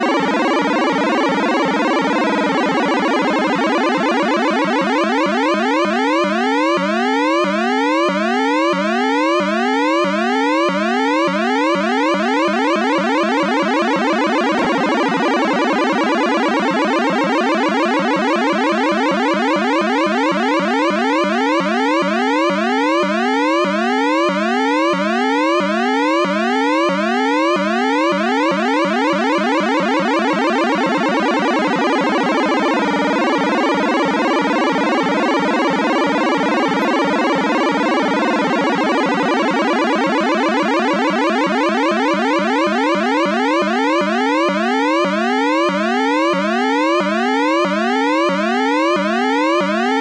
卡通 " 卡通21
描述：在Roland System100老式模块化合成器上重现了卡通式警笛
标签： 卡通 科幻 警报器 合成
声道立体声